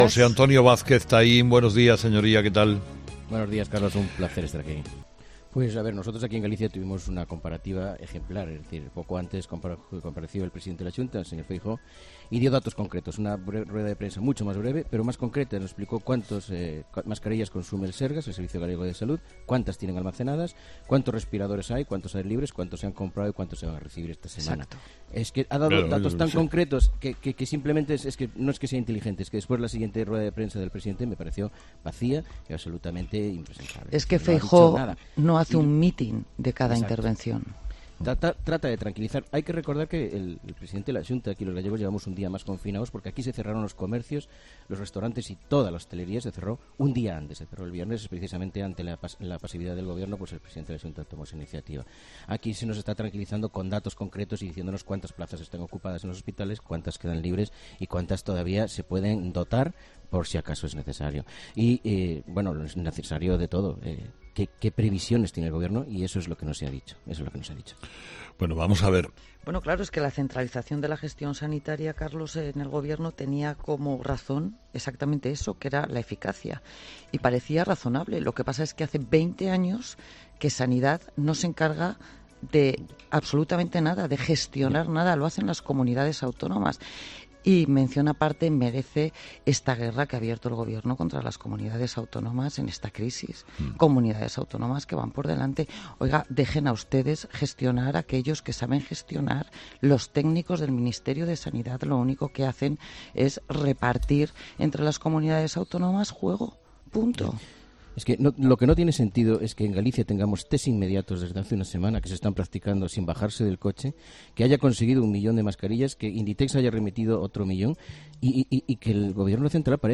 Entrevistado: "José Antonio Vázquez Taín"